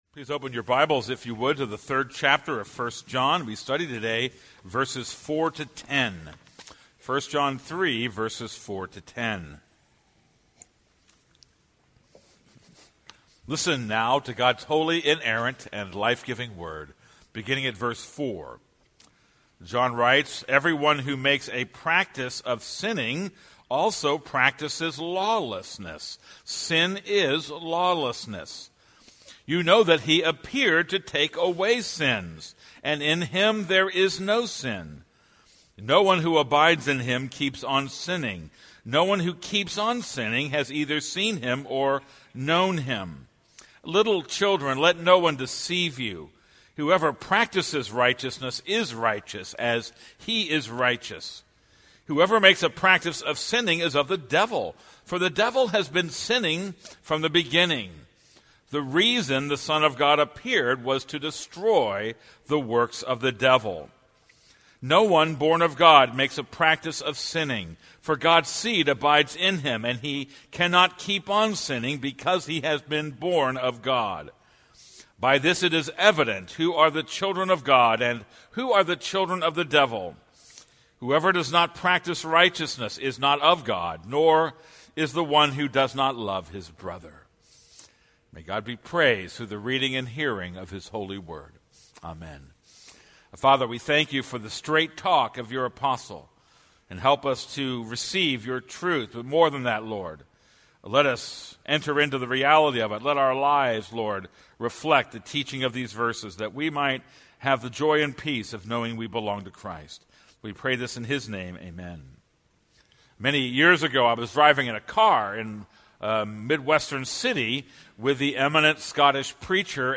This is a sermon on 1 John 3:4-10.